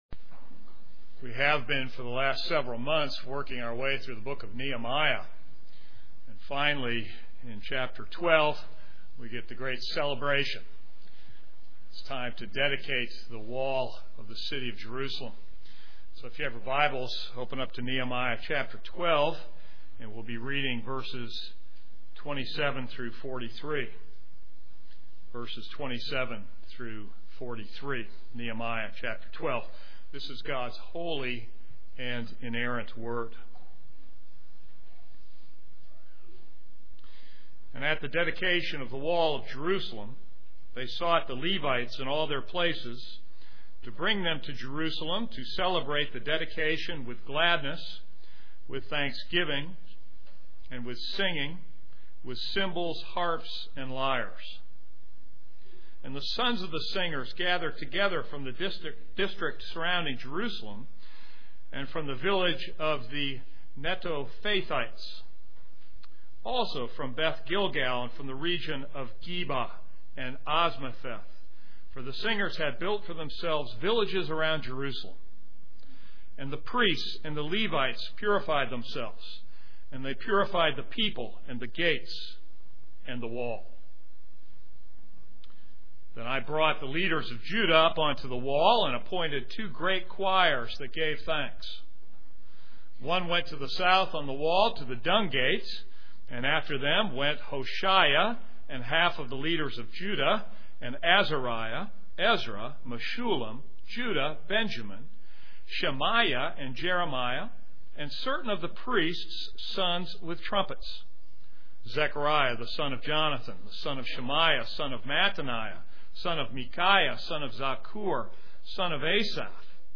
This is a sermon on Nehemiah 12:27-43.